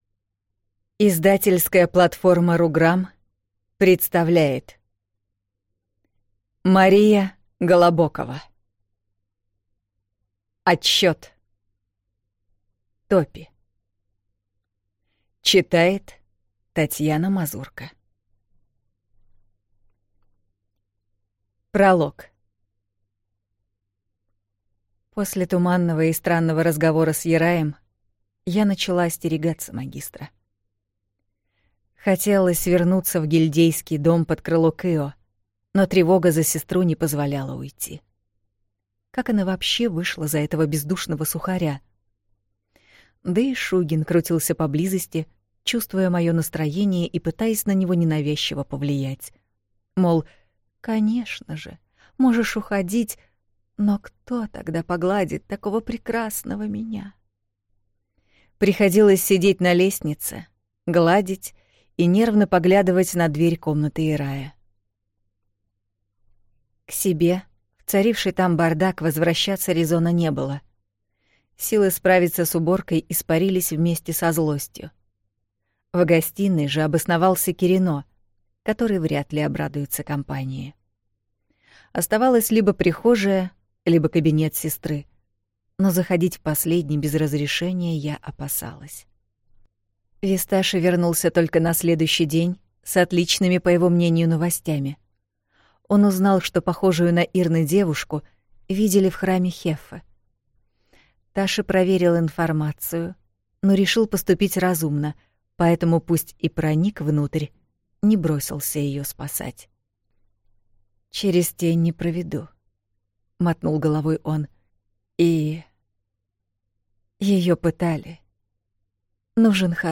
Аудиокнига Отсчет. Топи | Библиотека аудиокниг